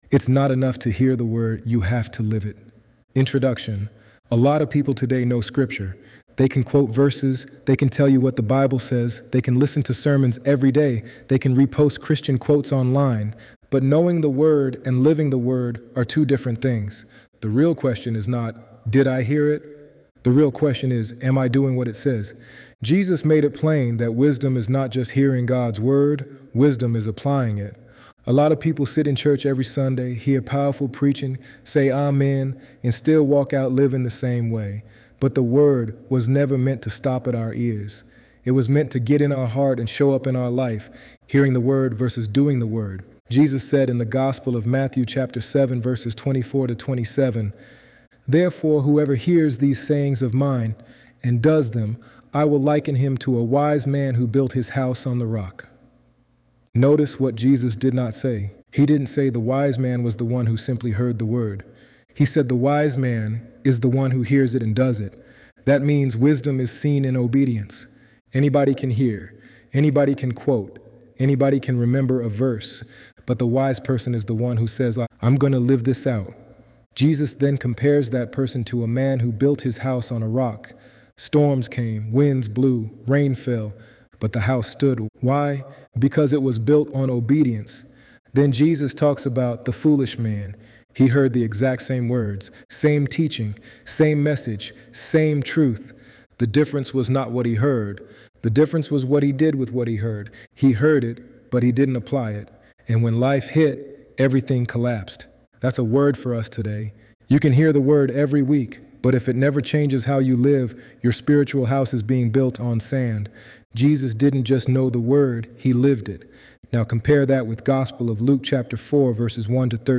ElevenLabs_Untitled_project.mp3